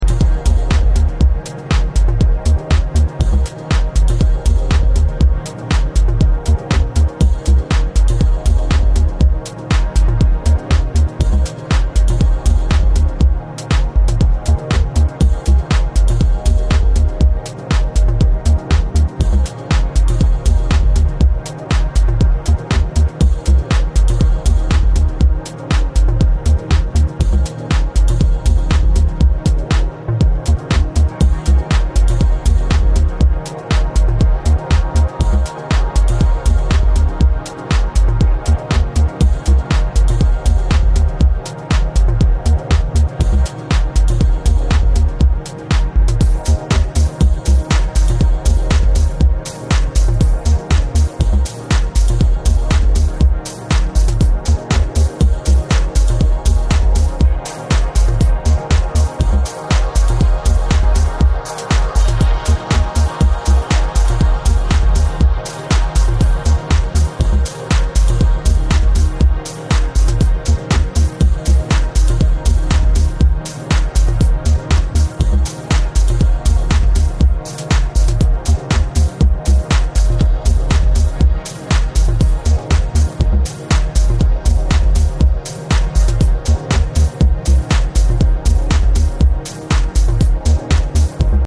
gloriously deep, beautifully produced music